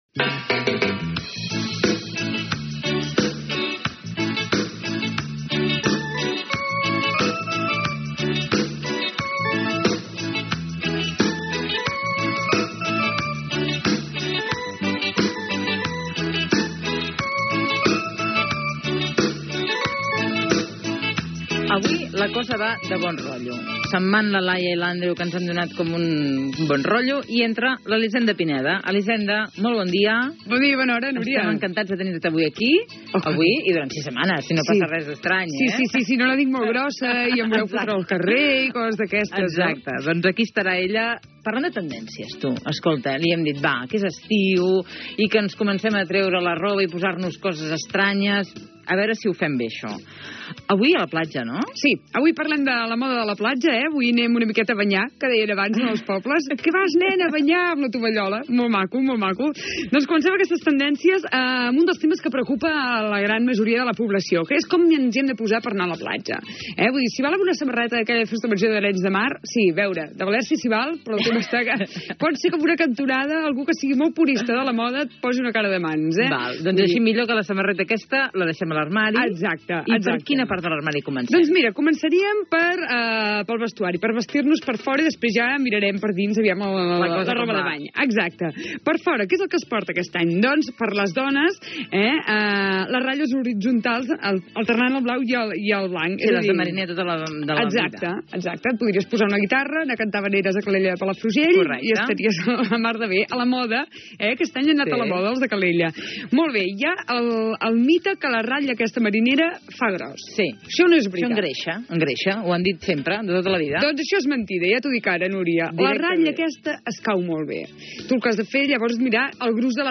La moda a la platja Gènere radiofònic Info-entreteniment